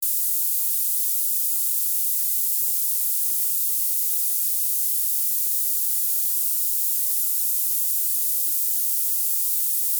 In the second test the coders are given the task to code a white noise signal, which was passed through a digital HPF with a 10 kHz cutoff frequency and a moderate slope; this allows us to examine how the coders cope with a spectrally irregular signal in their weaker areas.
The 256 kbps version is very similar to that of LAME but with a little more audible artifacts.
noisehp_blade256.wav